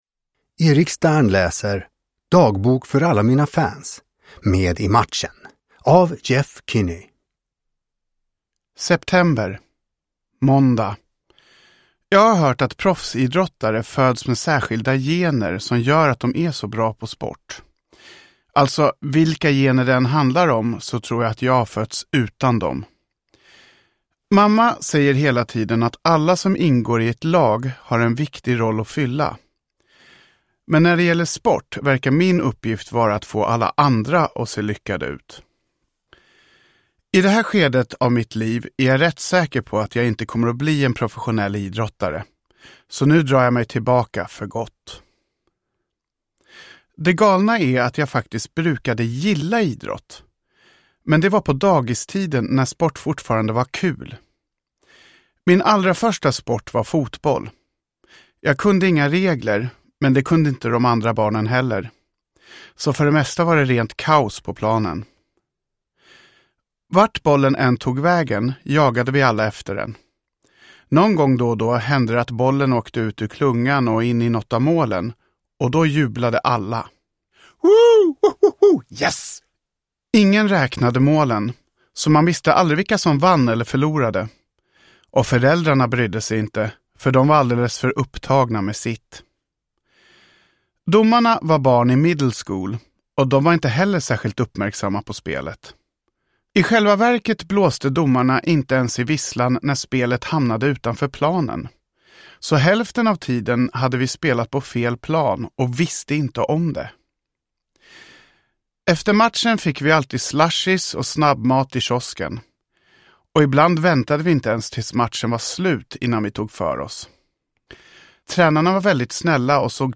Med i matchen – Ljudbok – Laddas ner